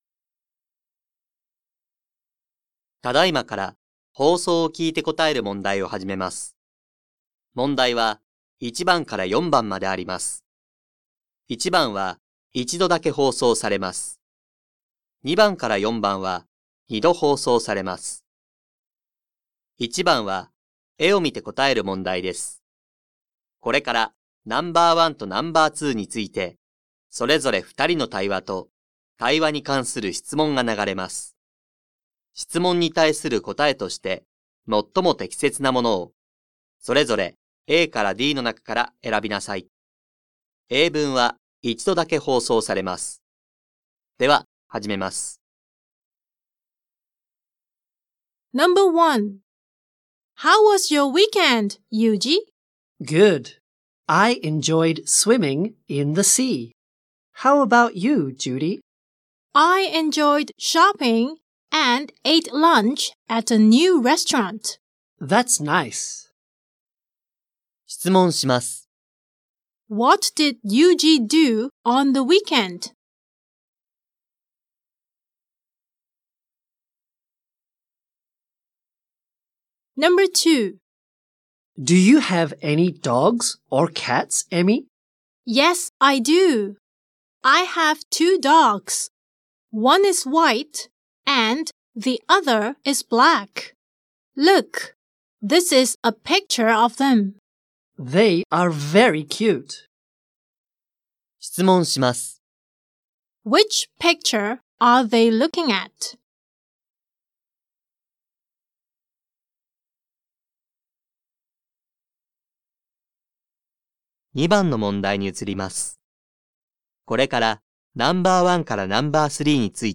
2025年度２年１号英語のリスニングテストの音声